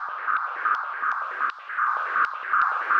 Index of /musicradar/rhythmic-inspiration-samples/80bpm